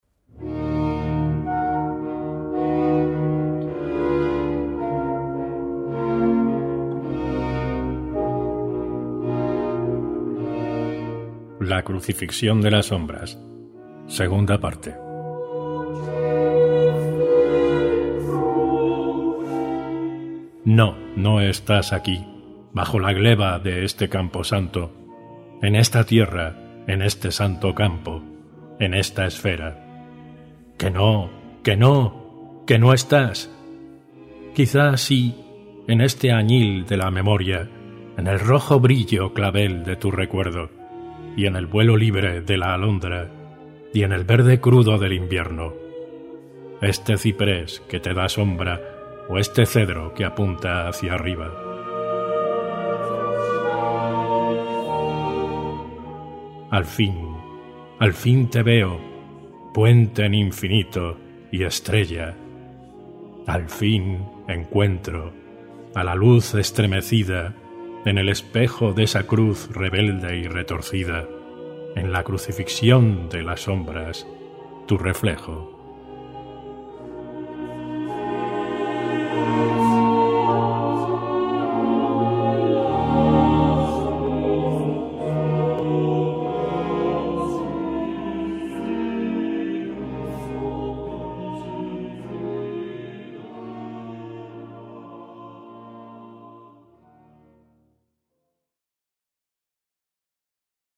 Poema
Selección Musical: La pasión según San Mateo (J. S. Bach)